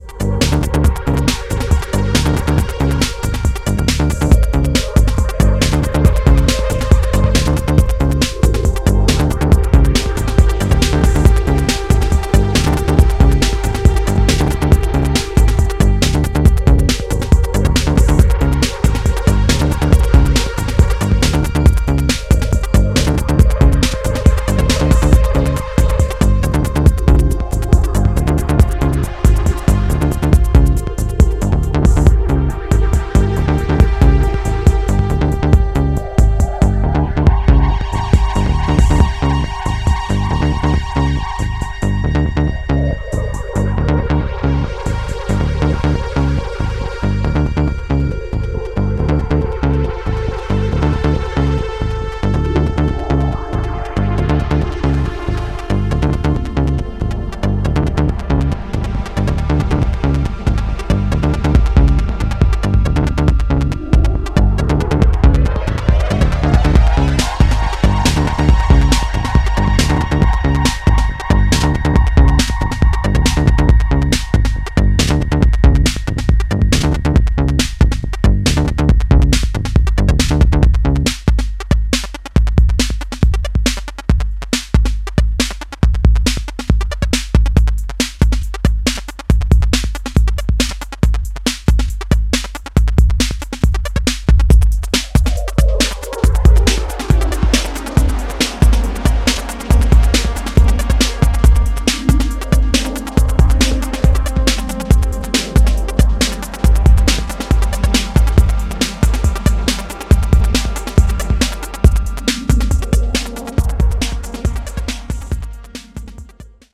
Electro masters.